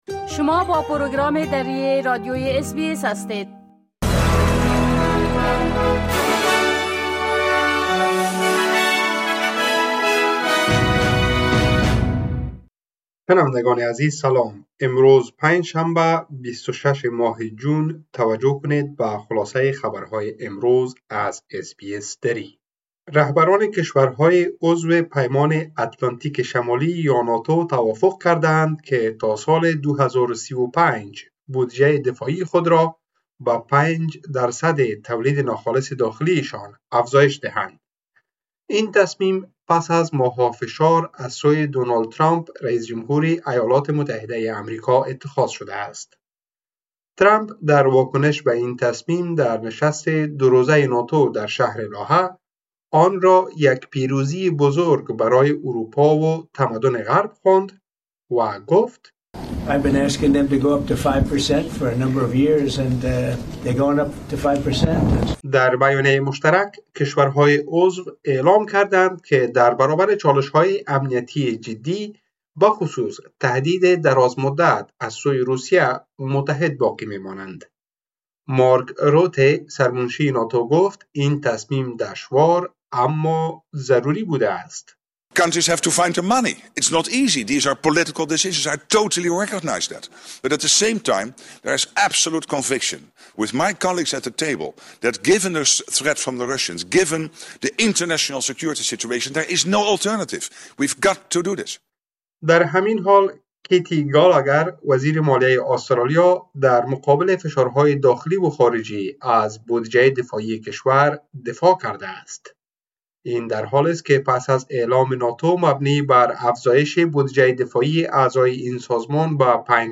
خلاصه مهمترين خبرهای روز از بخش درى راديوى اس‌بى‌اس | ۲۶ جون